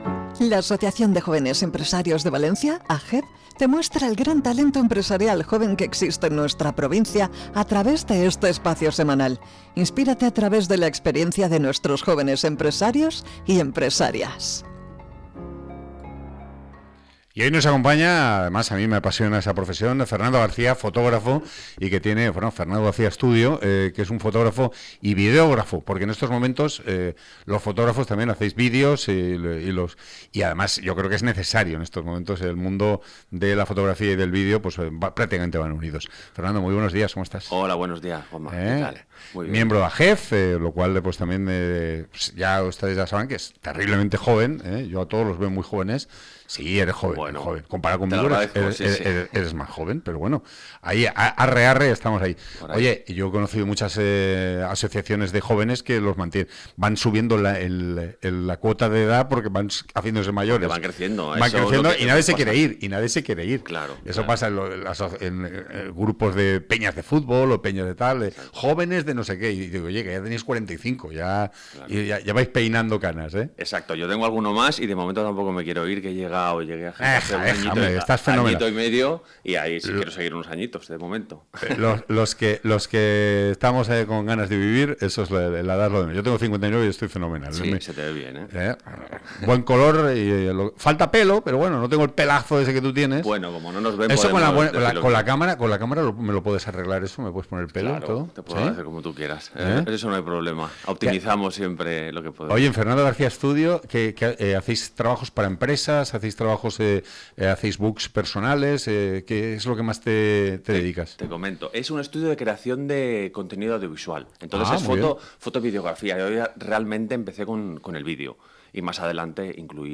AJEV